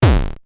combat_enemy_hit.mp3